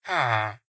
mob / villager / idle1.ogg